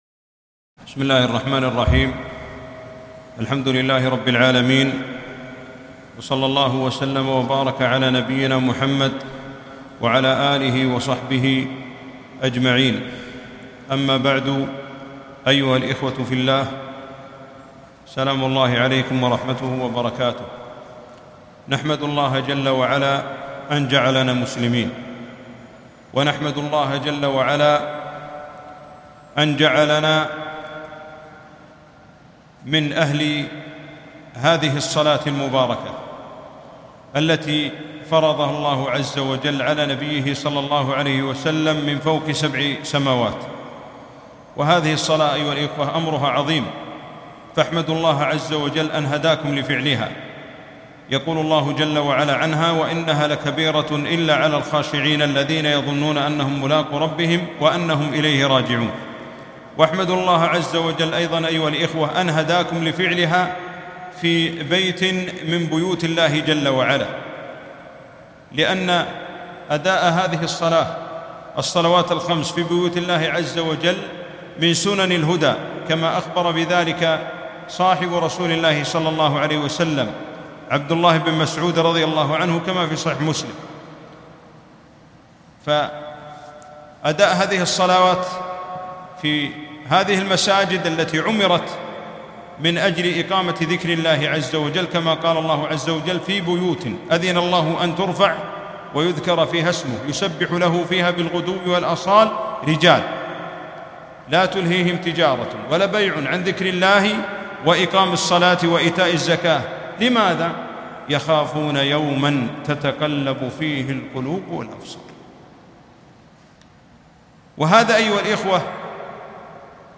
محاضرة - مراقبة الله تعالي كيفيتها وأثارها 2-4-1442